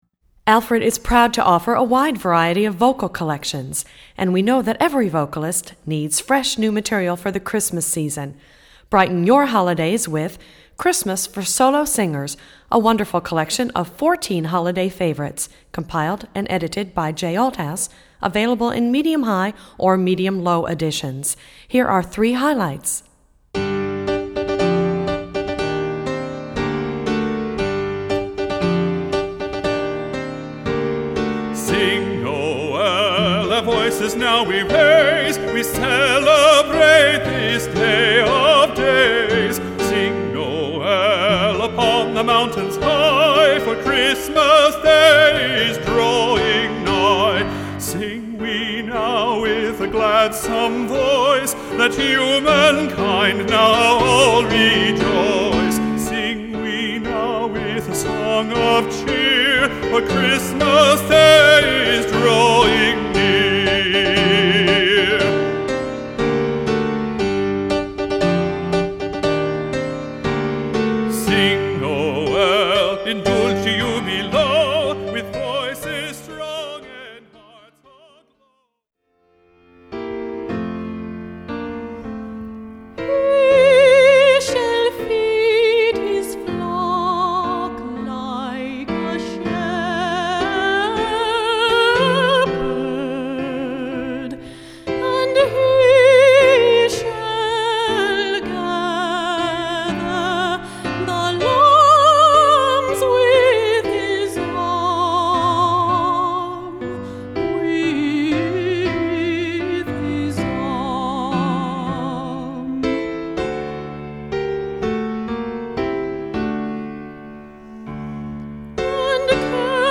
Voicing: Medium-Low Voice